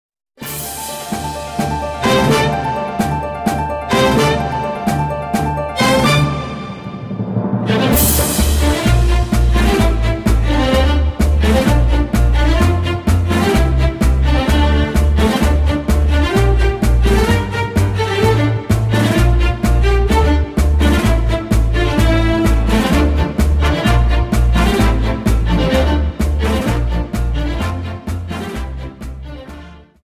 Dance: Tango